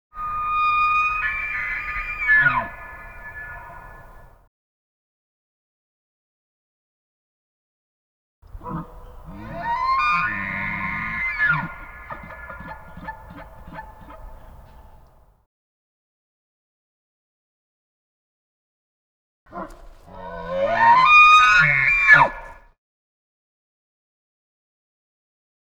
animal
Elk Cry Calls